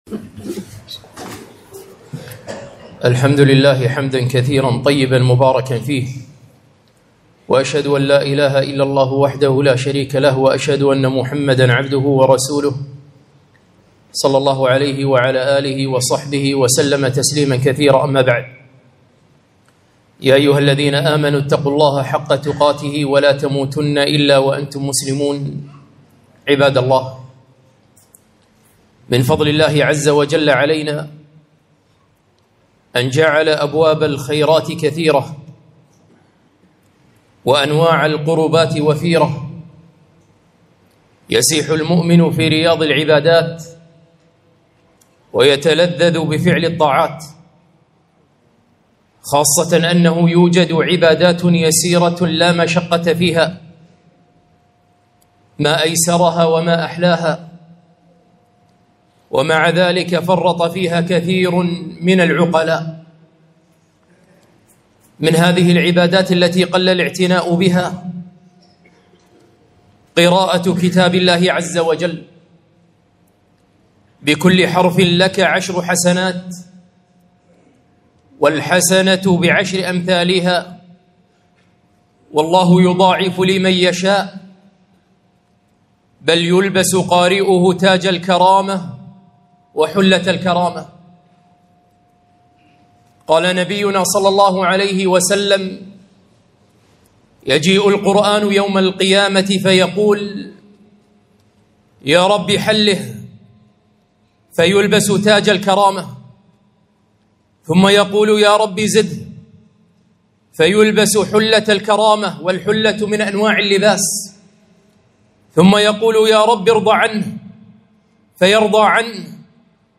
خطبة - هلا أقبلنا على الخير؟